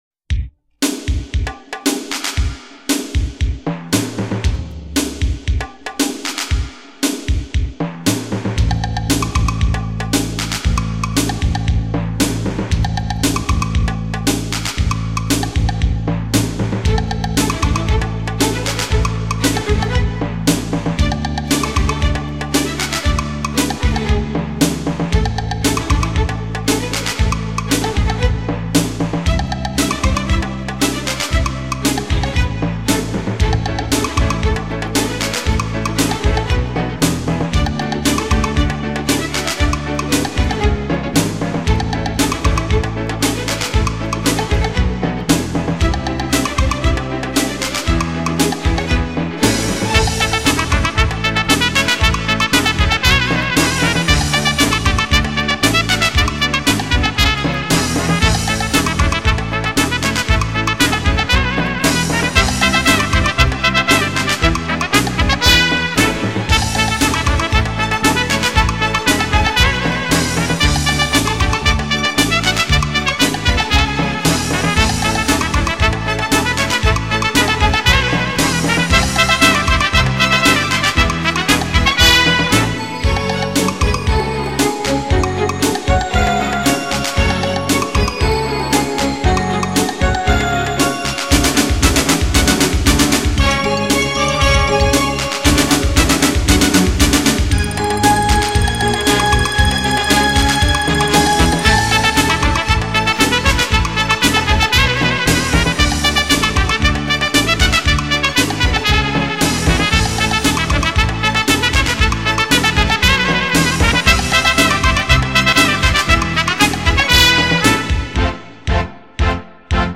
简介：囊罗世界顶级大师名曲名作，古典超值珍藏版，有没旋律让灵感与激情肆意迸发。